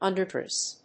アクセント・音節ùnder・dréss